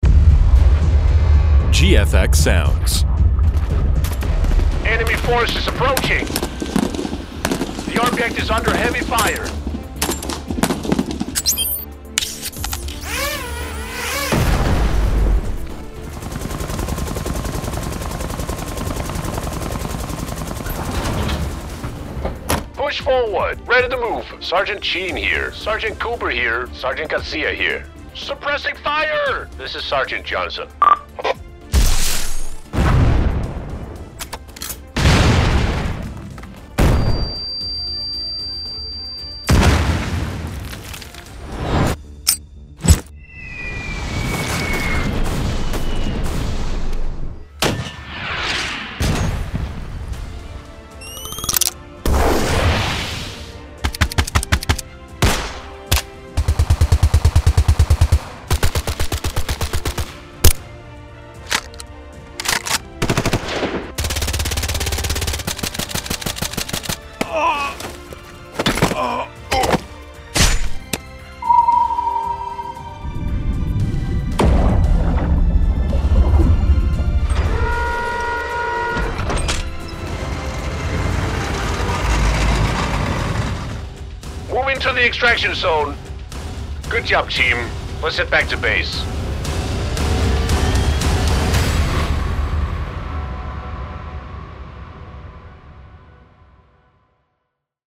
Inside you’ll find Explosions, Artillery fire, Rocket launches, Grenades, and heavy impacts, alongside a vast arsenal of Weapon Sounds including Assault Rifles, Machine Guns, Submachine Guns, Pistols, Shotguns, and Sniper Rifles, with shots, burst fire, reloads, mechanisms, and handling. The pack also includes immersive War Ambiences, close combat sounds, tactical gear and military foley, Vehicles such as Helicopters, Trucks, Tanks, Ships, and Convoys, plus authentic Communications, radios, alarms, and battlefield signals.
Type: Cinematic, War, Animation
SFX Pack – Demo. (Please note that all audio watermarks will be removed on download)
military-warfare-sfx-pack-preview.mp3